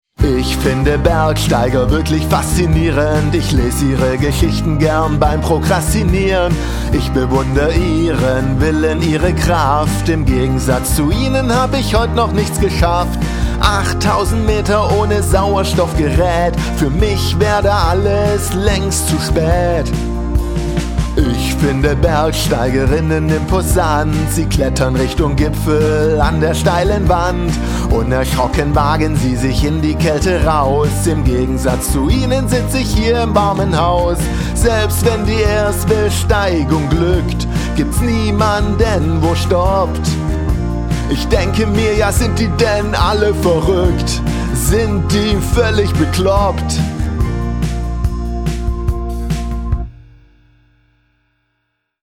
Musikalisch war ich inspiriert vom Mike Oldfield der 80er, auch wenn man das aufgrund meines Gesangs nicht mehr hört.